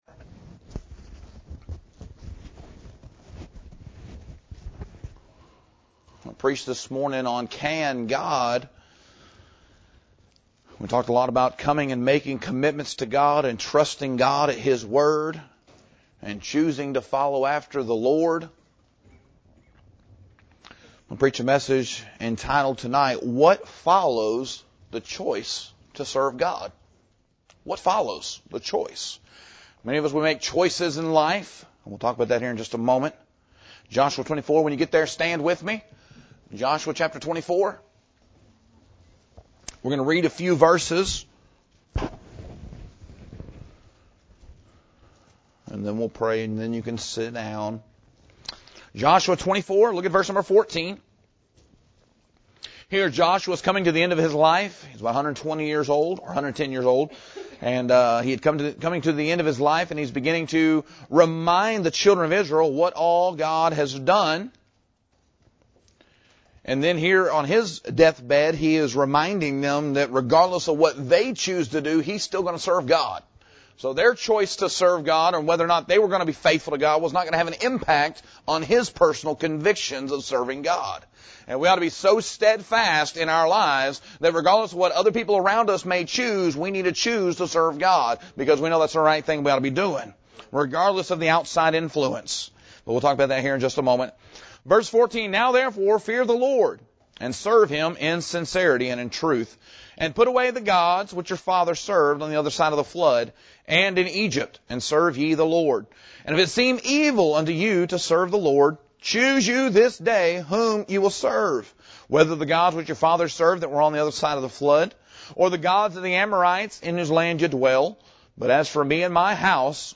This sermon unpacks three clear results that follow the decision to serve God — accountability, abstinence, and adoration — and why each matters for your walk with the Lord.